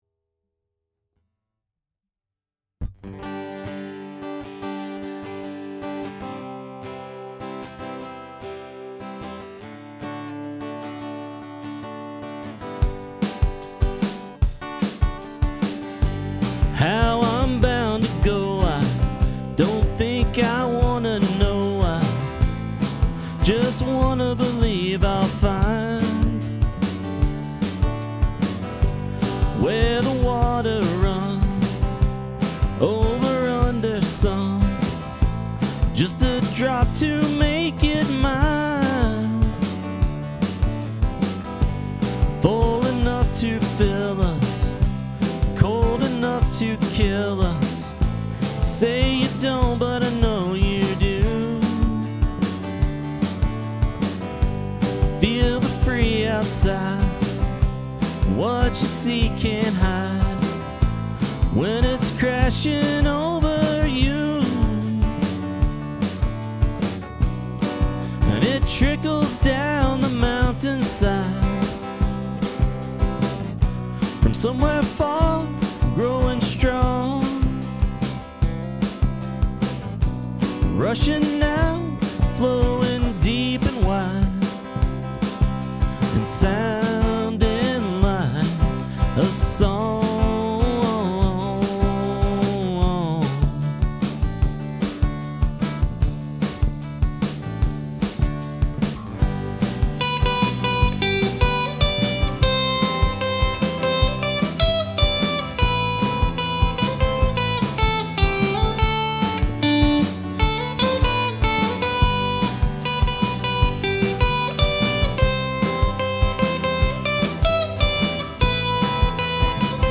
Finally got around to recording this one at home.
guitar
bass